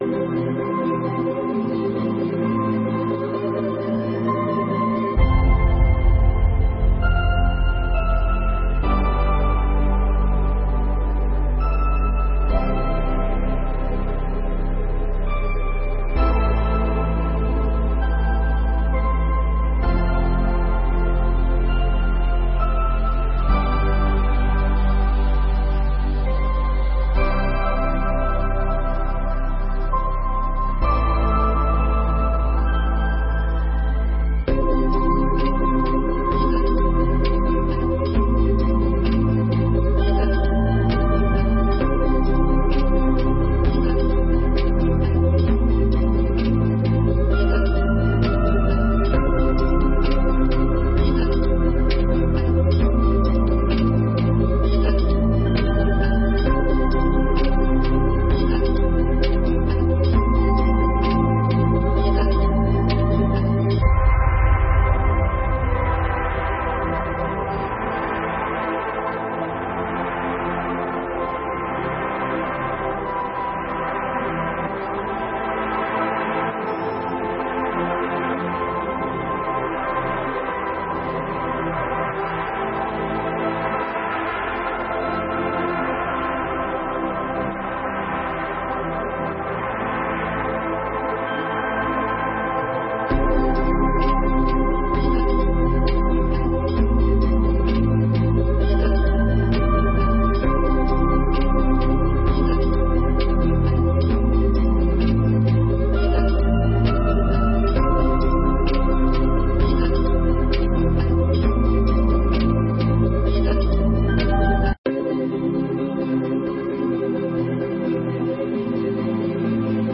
32ª Sessão Ordinária de 2021